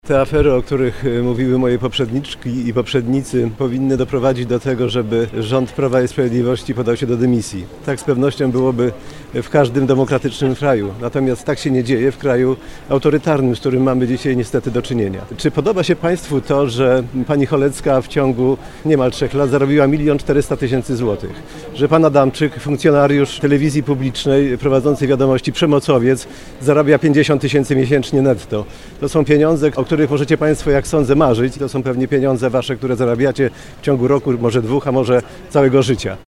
Mocnych słów użył także poseł Krzysztof Mieszkowski, były dyrektor Teatru Polskiego we Wrocławiu.